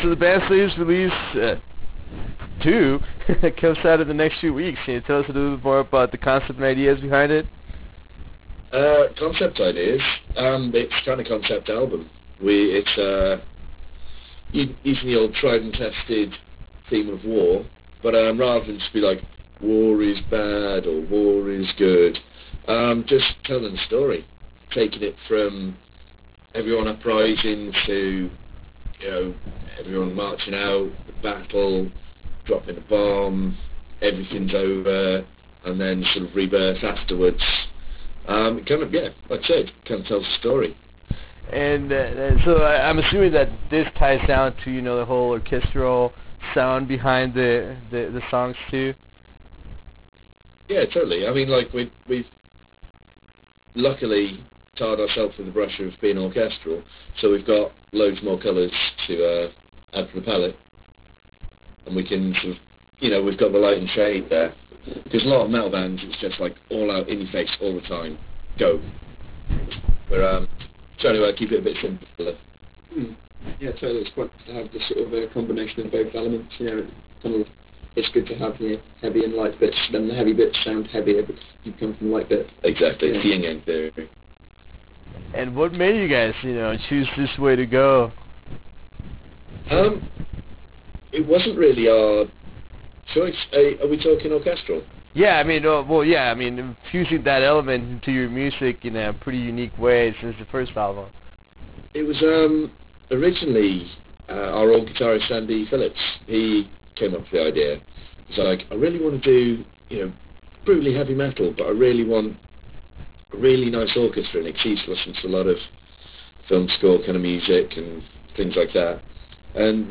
With the impending release of their monumental second album “II”, we managed to have a conversation with a few of the band members of Xerath. In this interview we discuss the changes the band wanted to make for this high orchestral release.